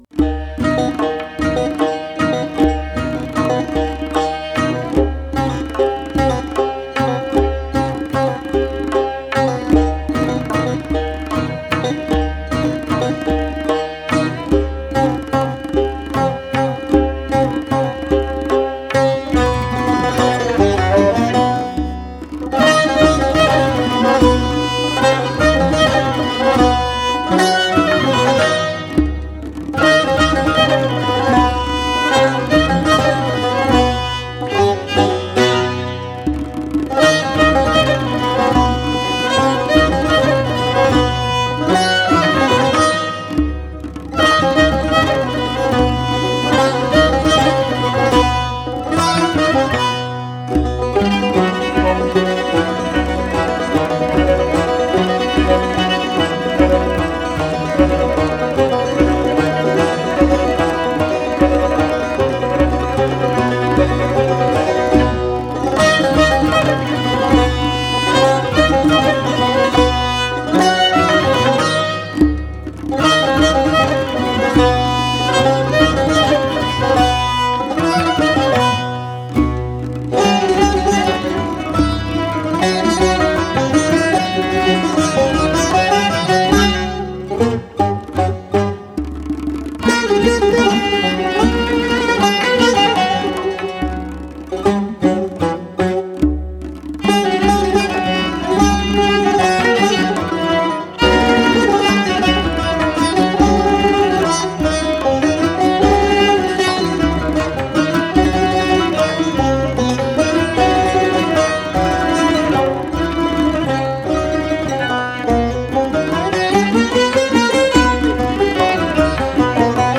Tasnif Chahargah